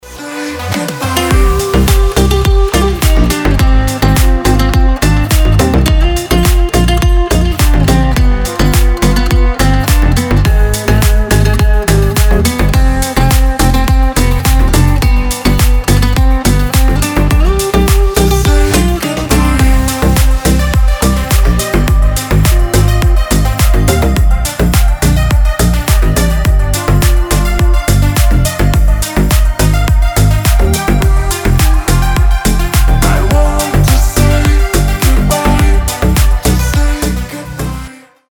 • Качество: 320, Stereo
мужской голос
deep house
Приятный гитарный deep house